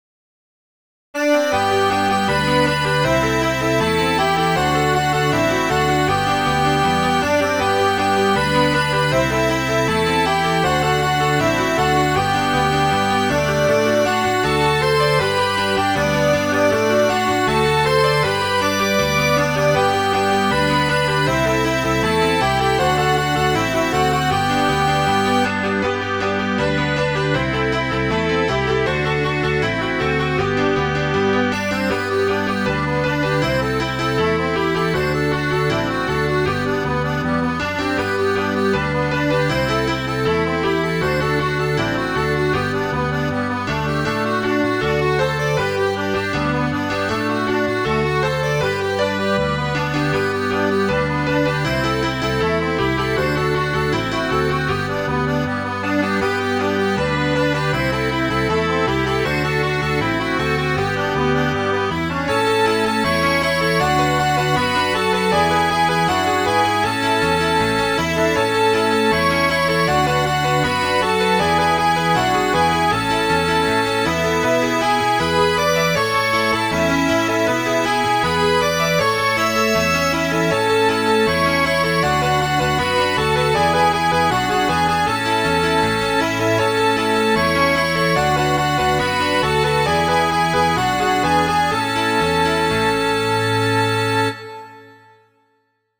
dew.mid.ogg